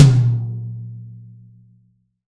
Index of /90_sSampleCDs/Best Service - Real Mega Drums VOL-1/Partition D/AMB KIT 02EC